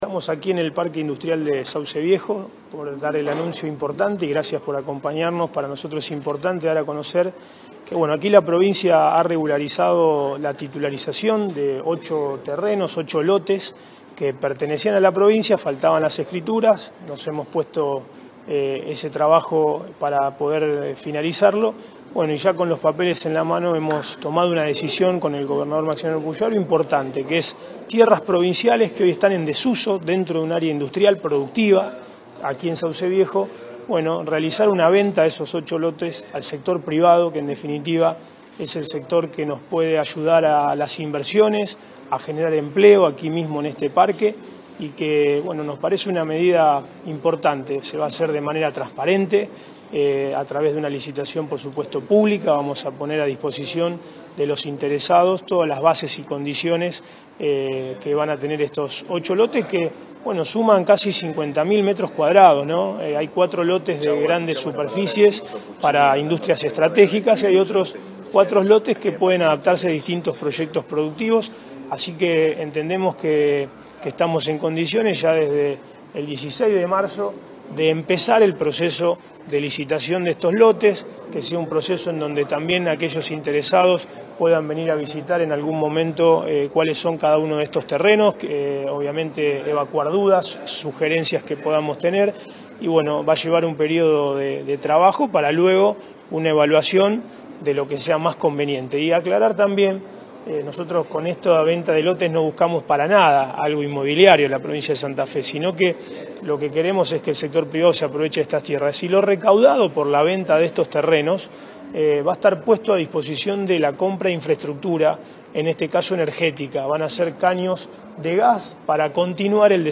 Gustavo Puccini, ministro de Desarrollo Productivo